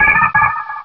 Cri de Coquiperl dans Pokémon Rubis et Saphir.